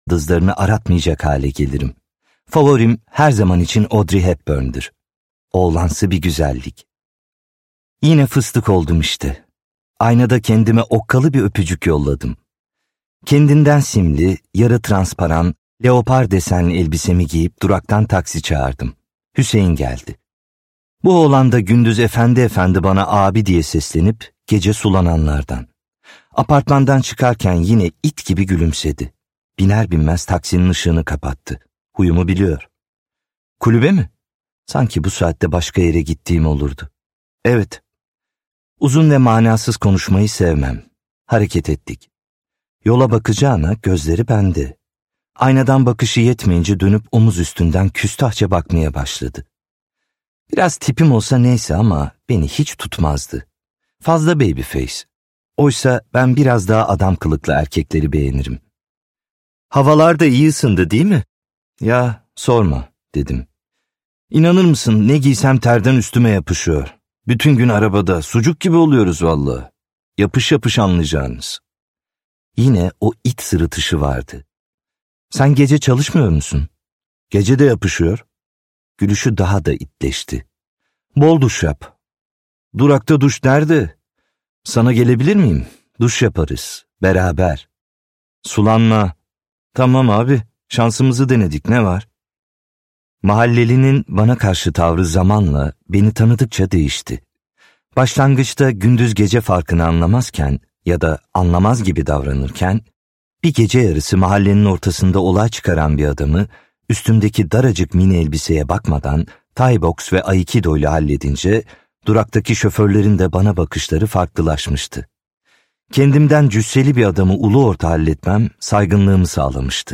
Buse Cinayeti - Seslenen Kitap